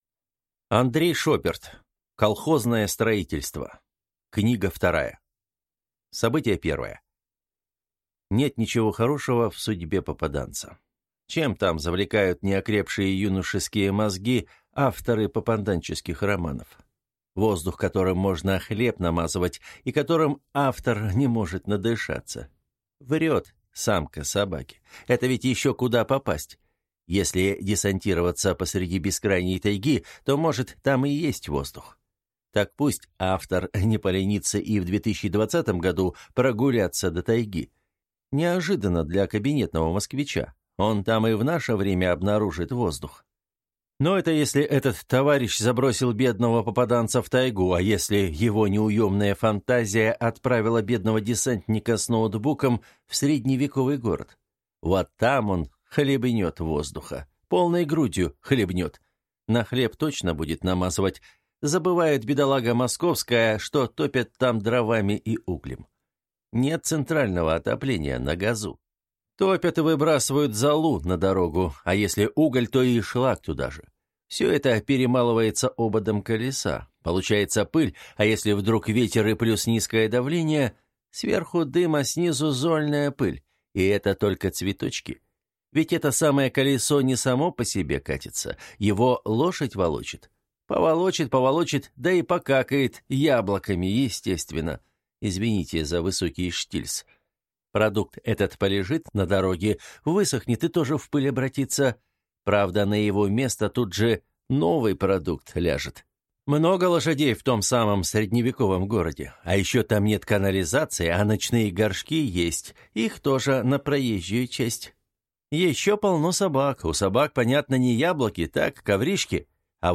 Аудиокнига Колхозное строительство 2 | Библиотека аудиокниг